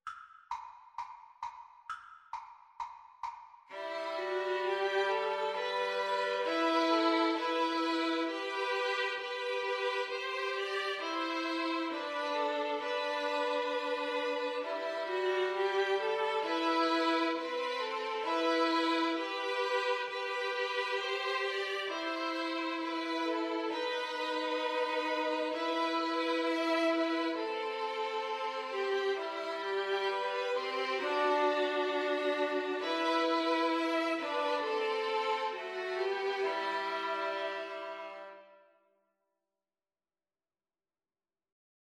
Violin 1Violin 2Cello
4/4 (View more 4/4 Music)